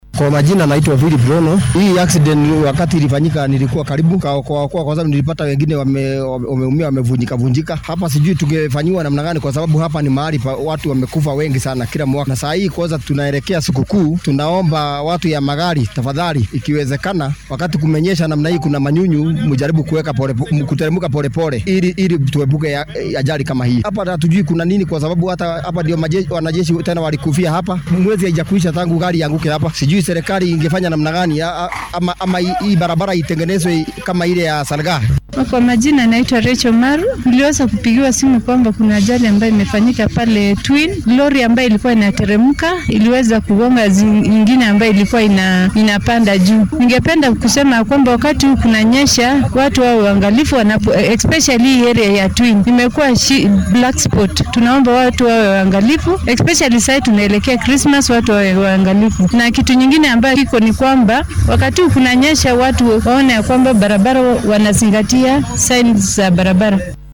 Goobjoogayaal-Shilka-Nakuru.mp3